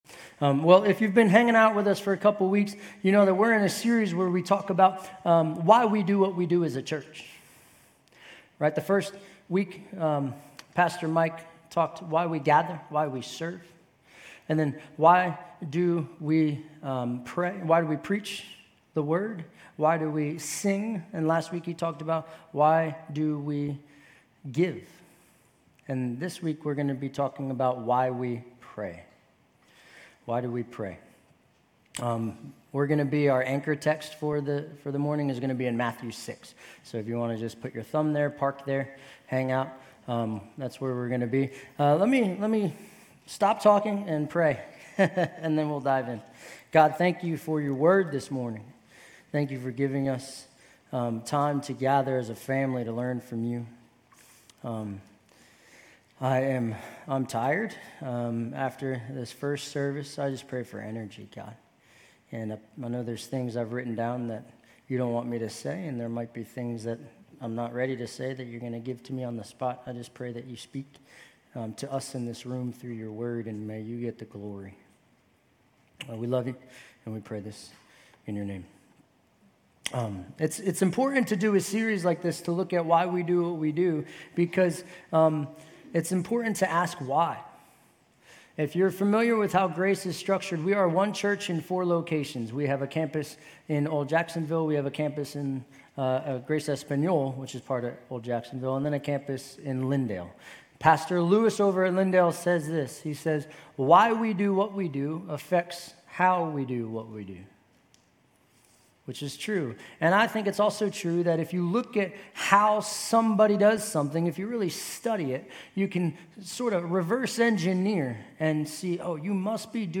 GCC-UB-September-24-Sermon.mp3